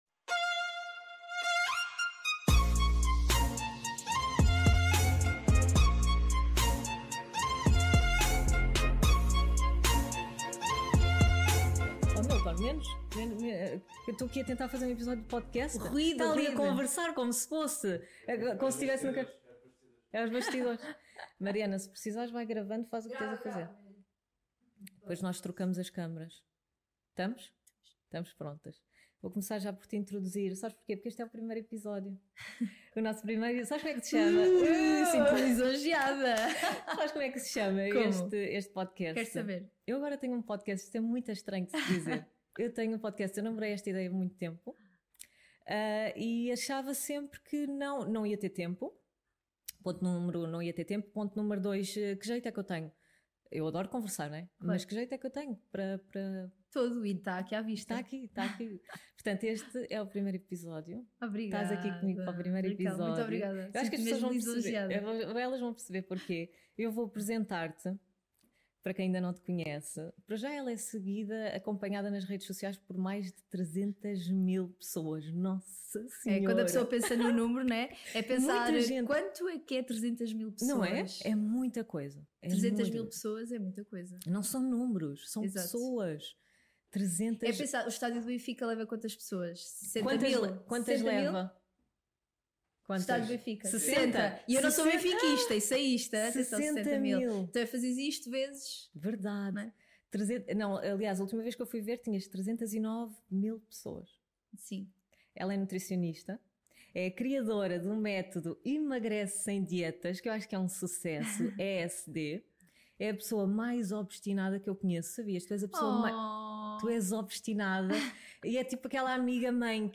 Um episódio que tem tanto de sério como de divertido, uma conversa inspiradora especialmente para outras mulheres empreendedoras.